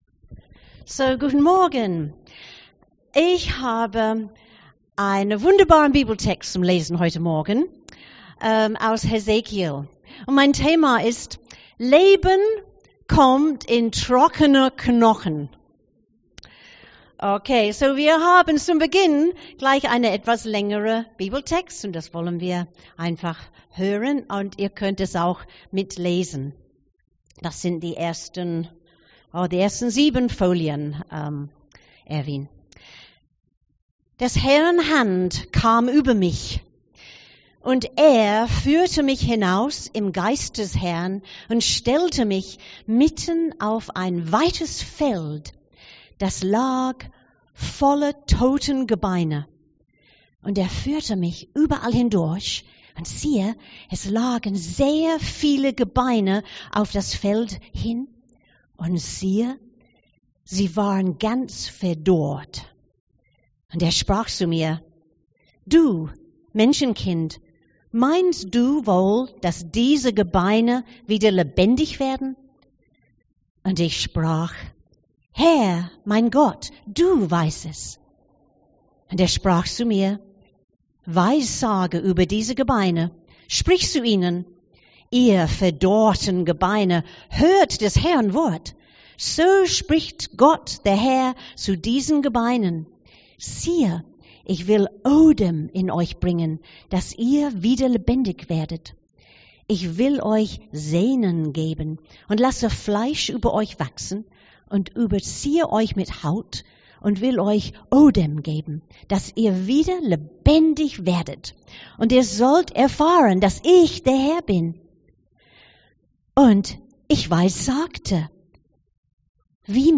Bibeltext zur Predigt: Hesekiel 37,1-14